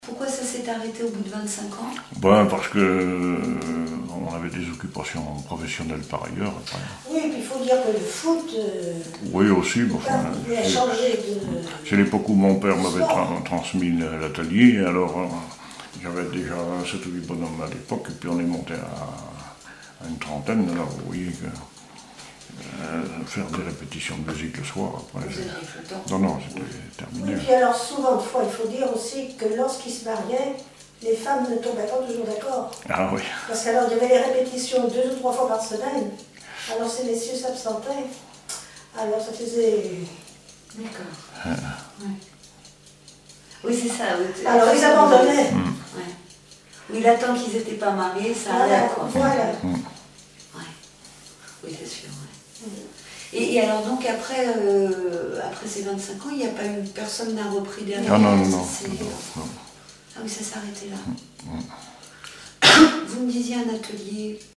témoignages sur la musique et une chanson